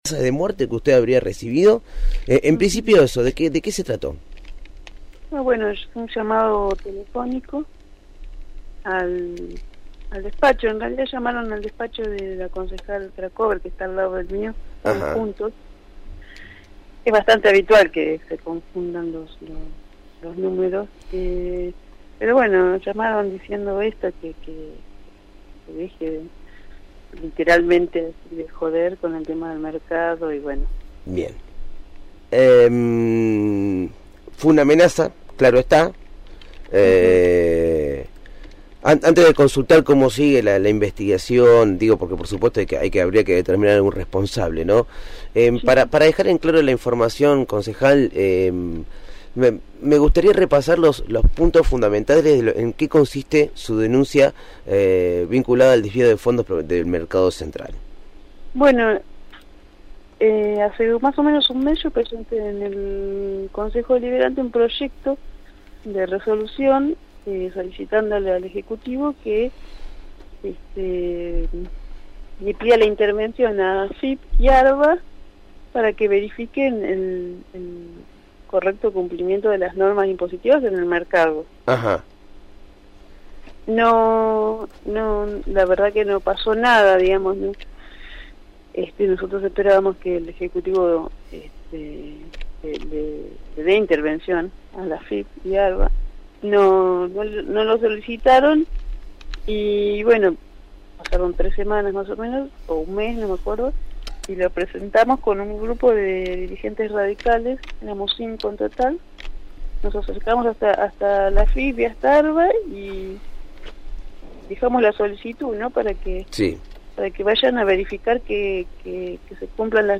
Florencia Rollié, concejal platense por la UCR, dialogó con el equipo de «El hormiguero»  sobre las amenazas de muerte que recibió, vinculadas a su trabajo en una denuncia para que se investigue el envío de dinero en bolsas desde el predio del Mercado Regional, ubicado en 520,  hacia el country Grand Bell, donde reside el intendente Julio Garro y otros funcionarios comunales.